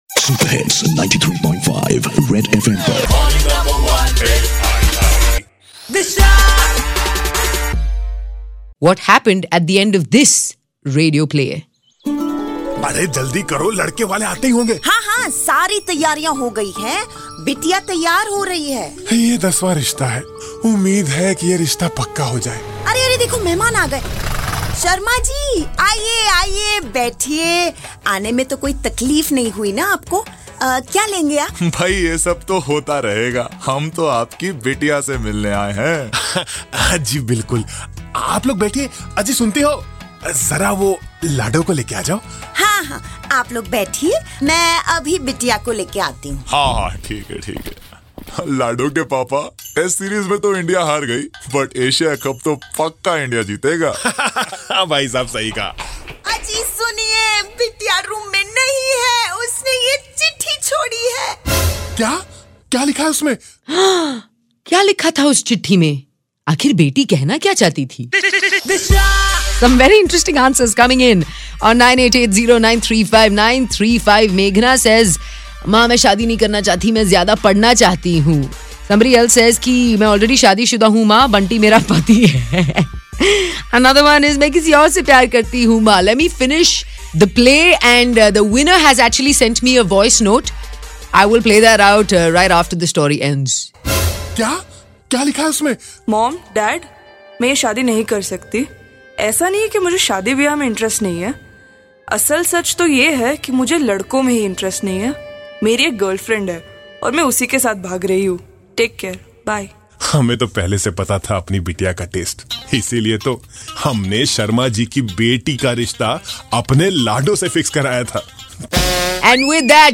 Radio Play on Sec 377